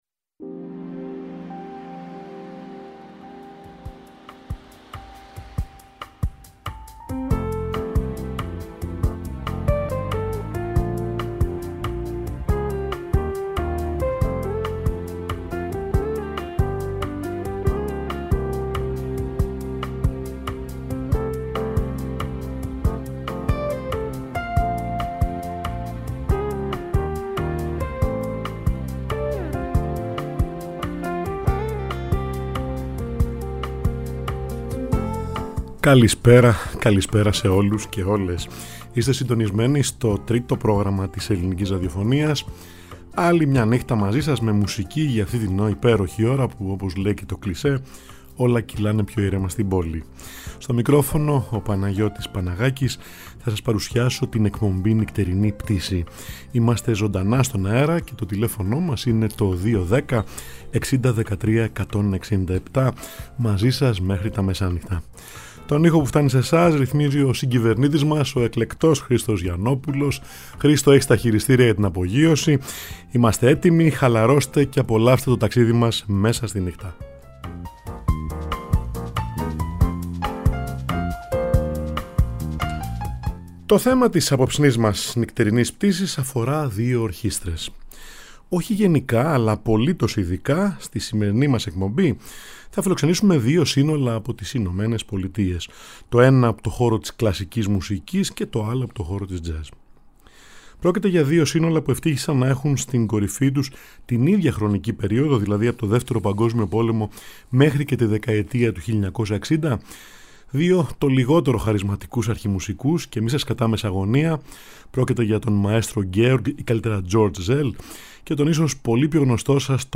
Δυο χαρισματικοί αρχιμουσικοί, δύο κορυφαίες ορχήστρες που μεσουράνησαν την ίδια εποχή: The Cleveland Orchestra, Duke Ellington Orchestra.